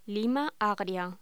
Locución: Lima agria
voz